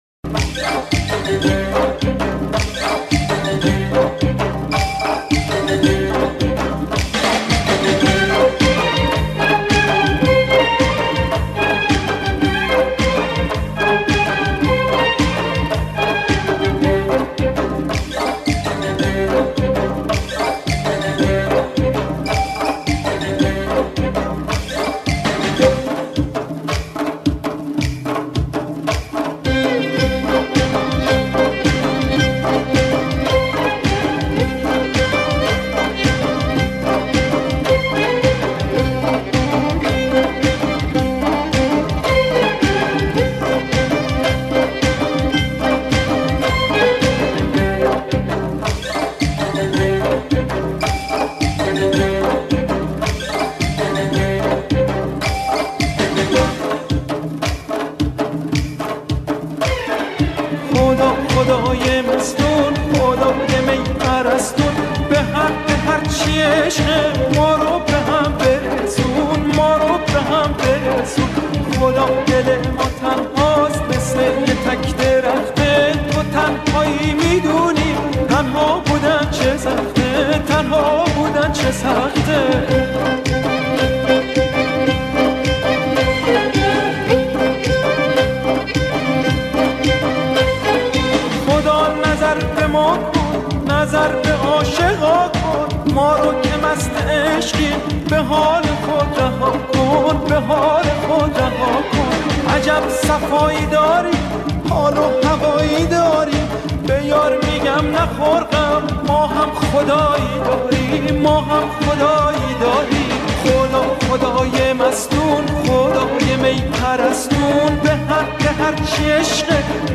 ژانر: پاپ قدیمی